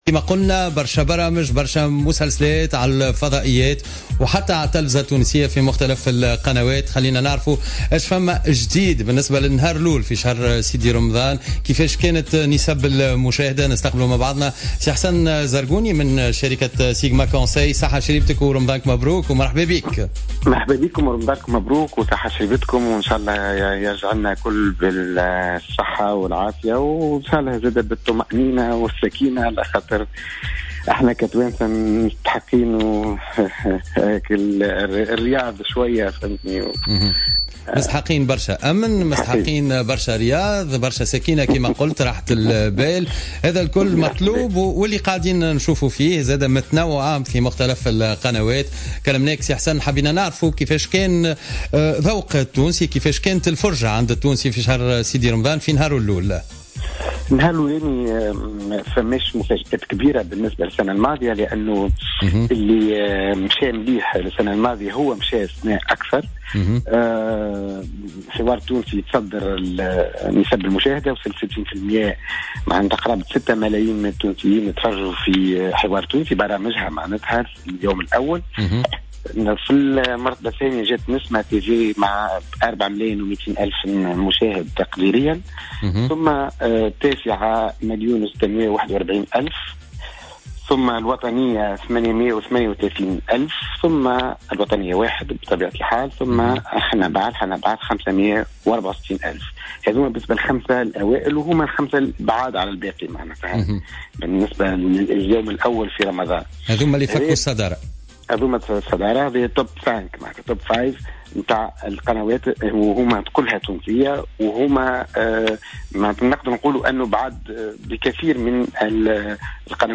خلال مداخلته في برنامج "يامسهرني" على موجات الجوهرة اف ام.